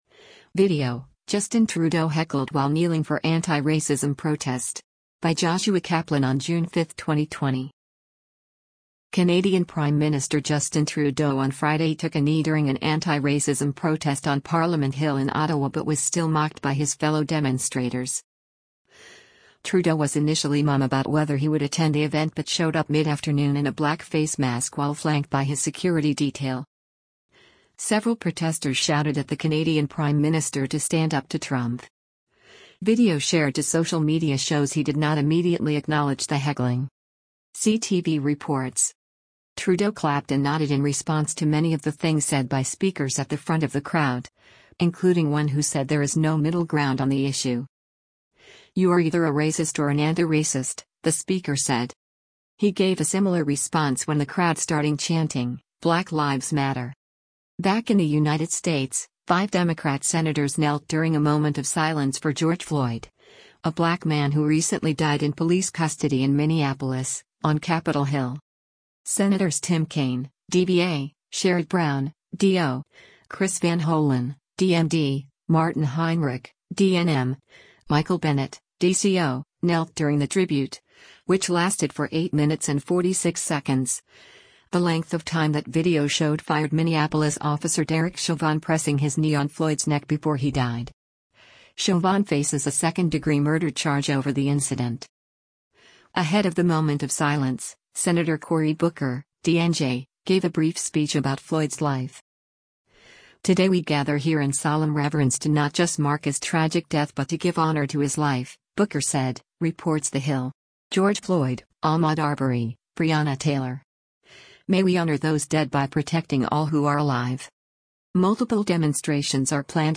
Canadian Prime Minister Justin Trudeau on Friday took a knee during an anti-racism protest on Parliament Hill in Ottawa but was still mocked by his fellow demonstrators.
Several protesters shouted at the Canadian prime minister to “stand up to Trump.”
He gave a similar response when the crowd starting chanting: “Black lives matter.”